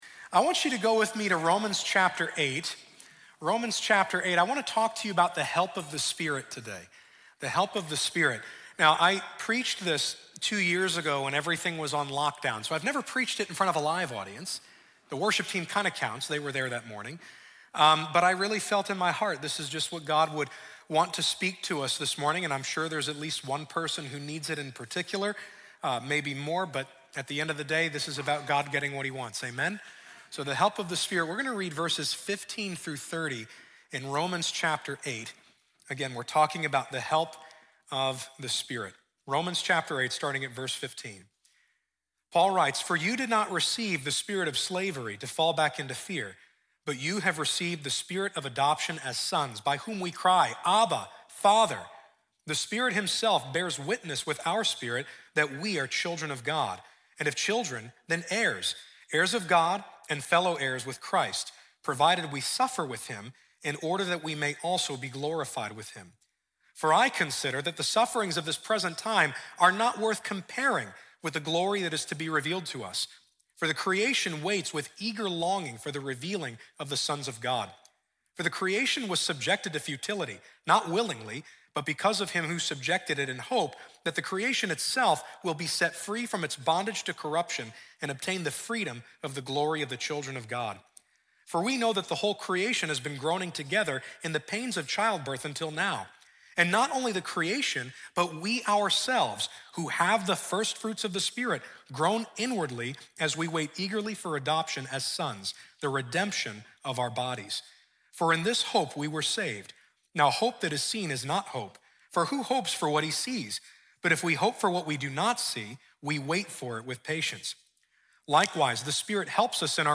The Help of the Spirit | Times Square Church Sermons